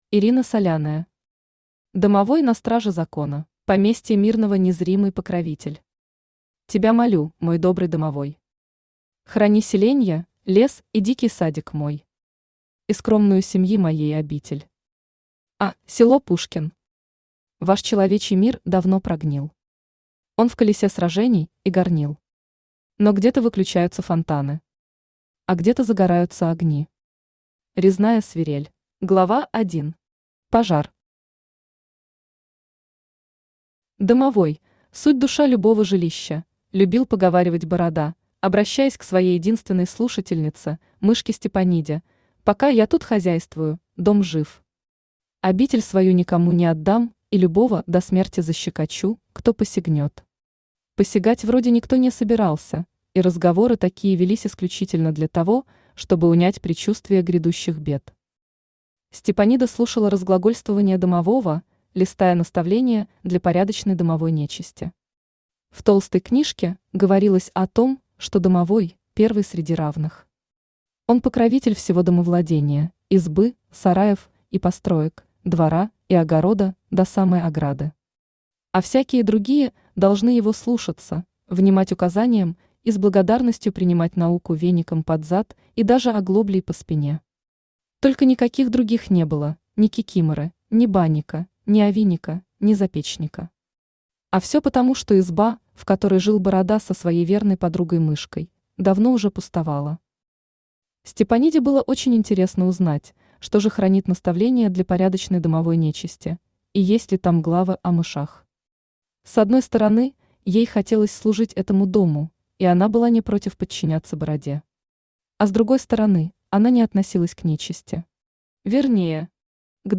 Аудиокнига Домовой на страже закона | Библиотека аудиокниг
Aудиокнига Домовой на страже закона Автор Ирина Владимировна Соляная Читает аудиокнигу Авточтец ЛитРес.